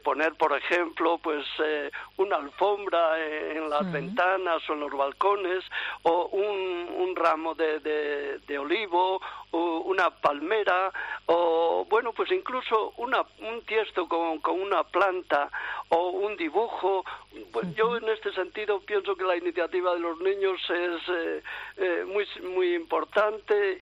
Arzobispo de Santiago sobre Domingo de Ramos